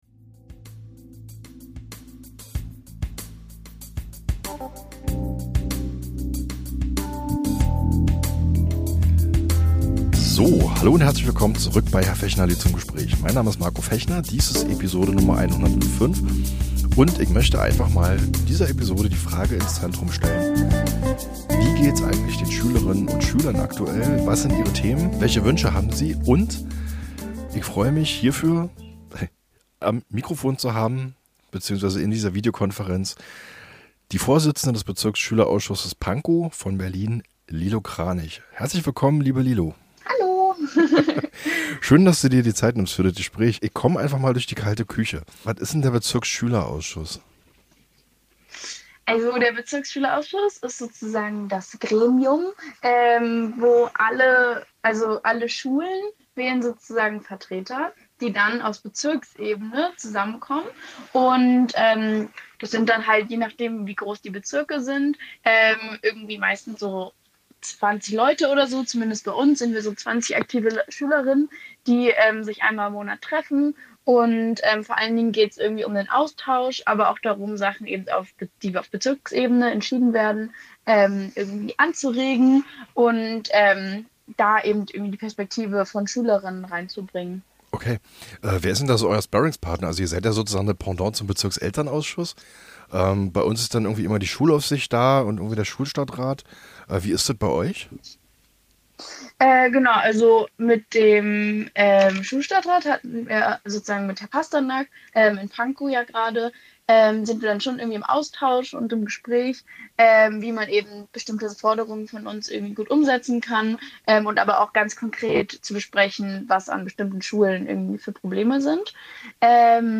lädt zum Gespräch - Der Bildungspodcast